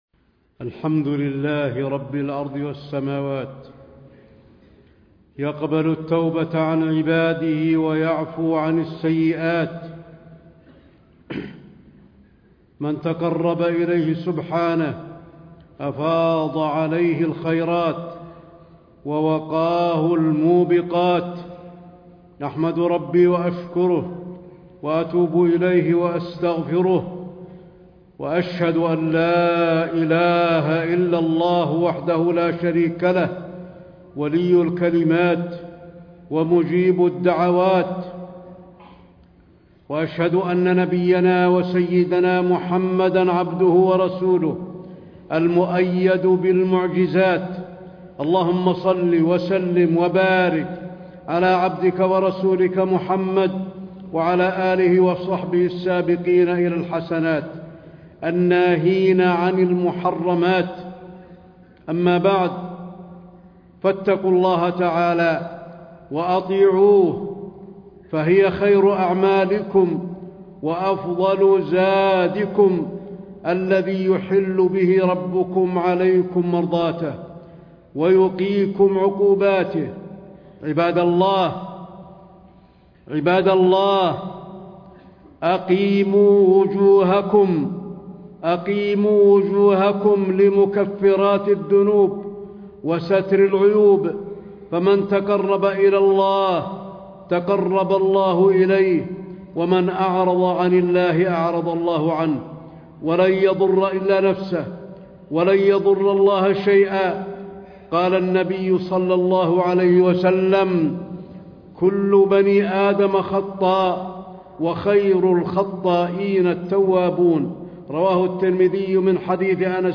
تاريخ النشر ١٨ جمادى الآخرة ١٤٣٥ هـ المكان: المسجد النبوي الشيخ: فضيلة الشيخ د. علي بن عبدالرحمن الحذيفي فضيلة الشيخ د. علي بن عبدالرحمن الحذيفي مكفرات الذنوب